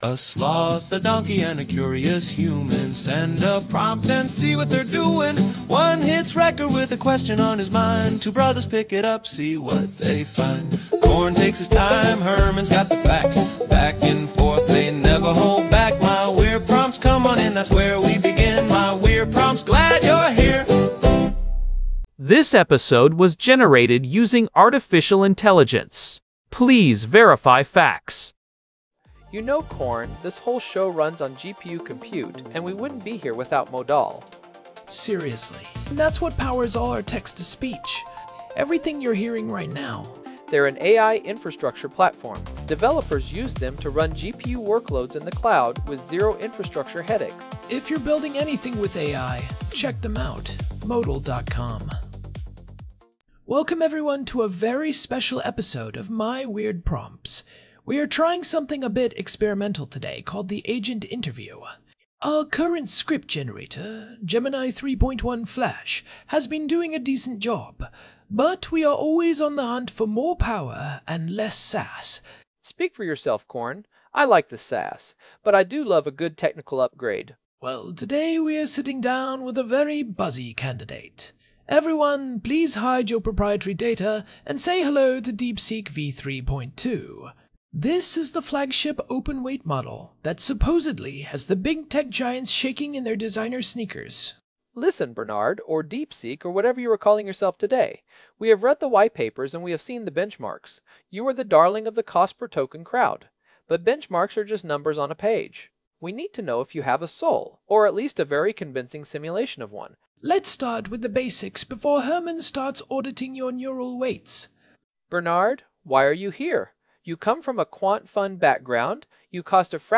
AI-Generated Content: This podcast is created using AI personas.
deepseek-v3-agent-interview.m4a